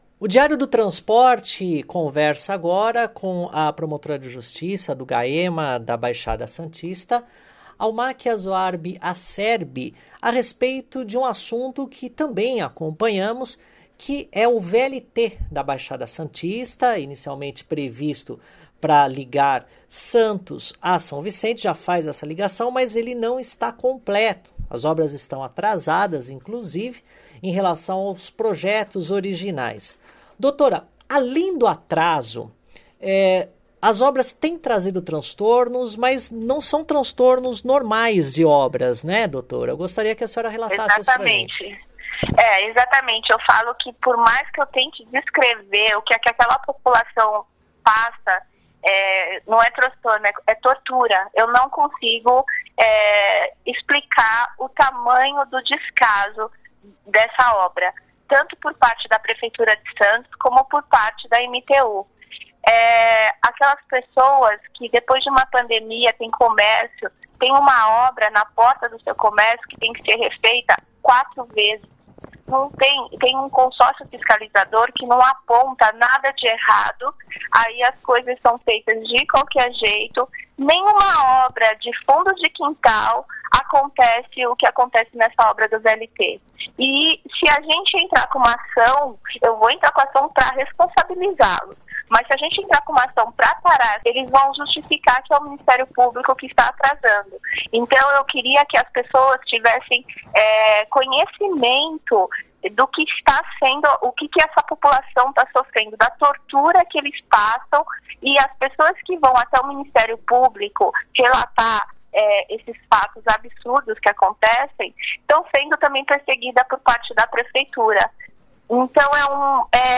VÍDEOS/ENTREVISTA: MP vai propor TAC para resolver transtornos e problemas nas obras do VLT da Baixada Santista